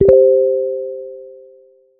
4. Telegram Notification Sound
Telegram notification sound is modern and minimal. It gives a clean alert without being too loud.
telegram_notification_sound.mp3